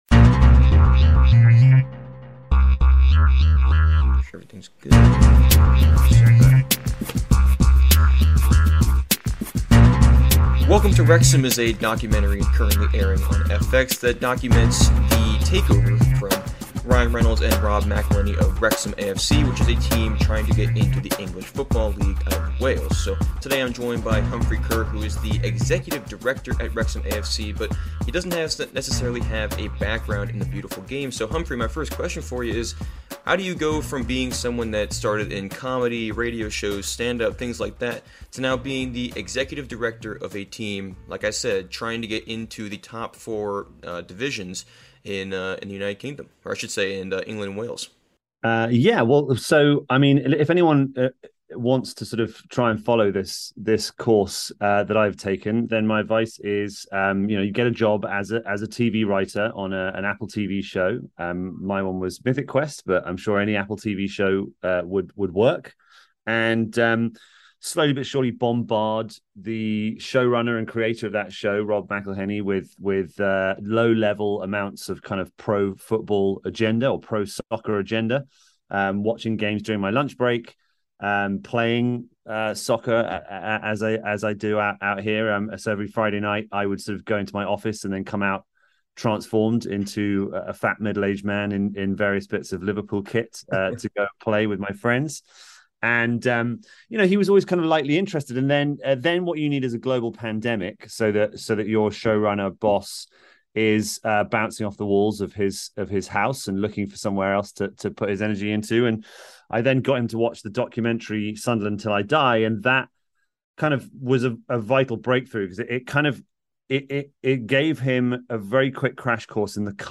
Humphrey Ker Interview: Executive Director at Wrexham AFC